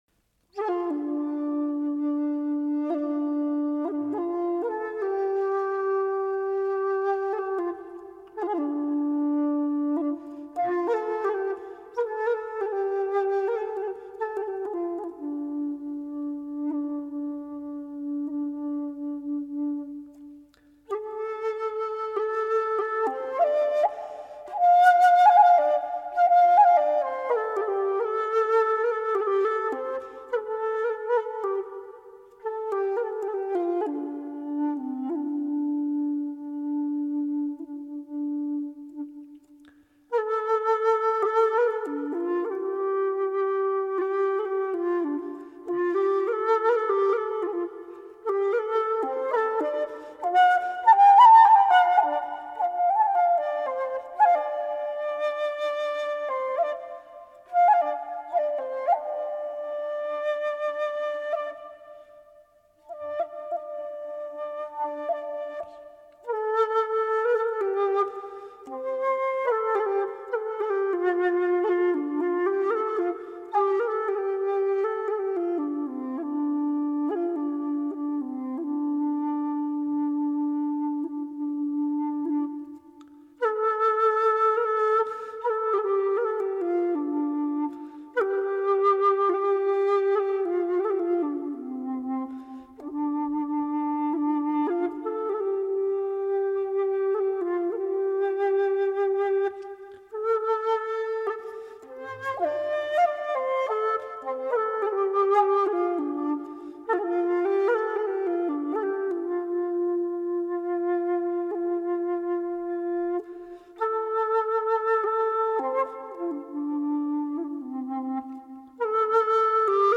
音乐类型：中国民乐
再次以古琴与萧演绎了元曲独特的艺术魅力。
或旷然悠远，或情真意切，或凄怆怨慕，或绮丽妩媚，